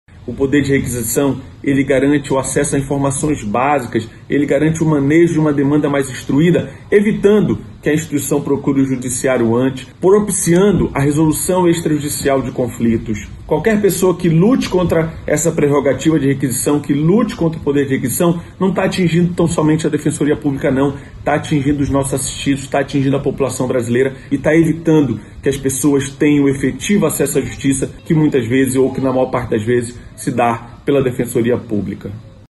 Sonora-2-Ricardo-Paiva-–-defensor-publico-geral-do-Amazonas.mp3